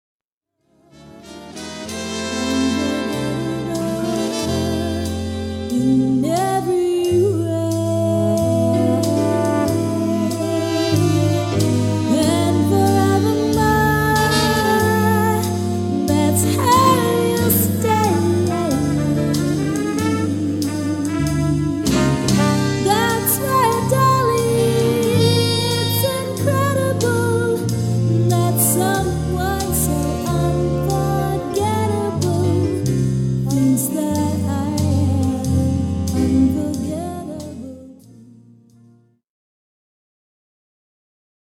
V = female vocal; MV = male vocal
Classic Big Band